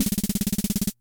Rapid Snare.wav